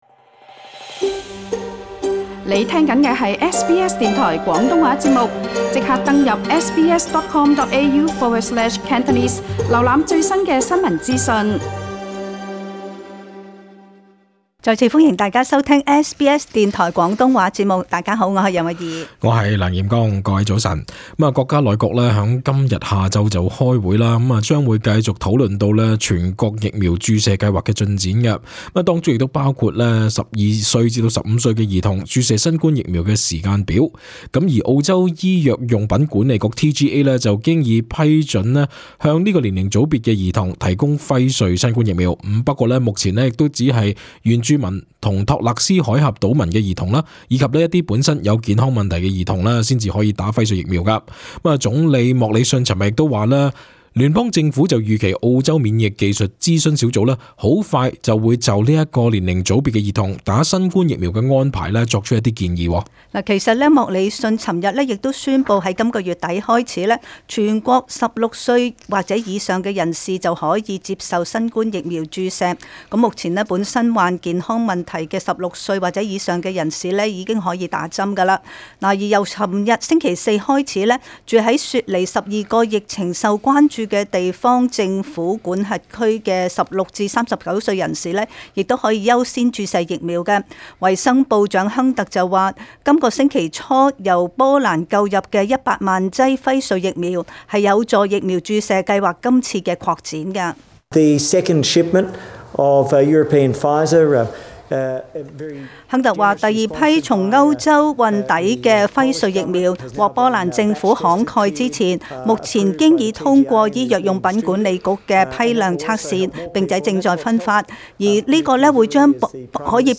時事報道